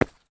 update stone step sounds
stone_2.ogg